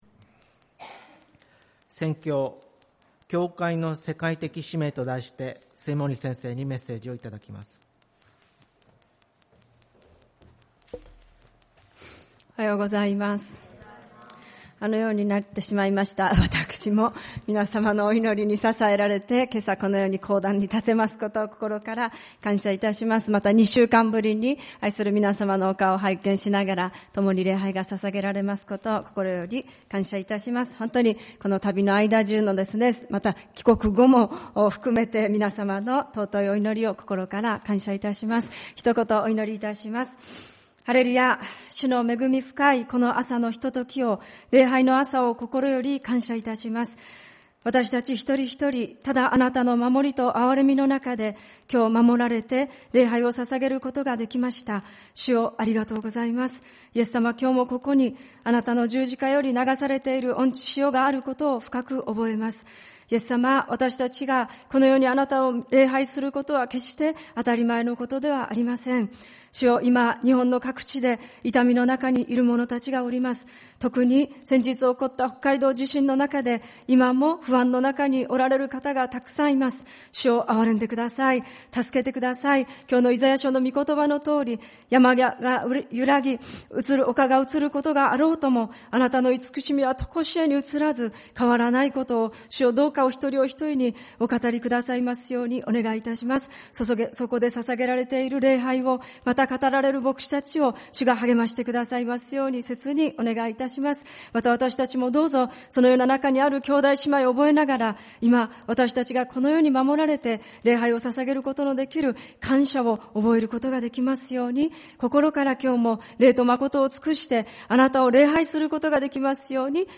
主日礼拝 「教会の世界的使命」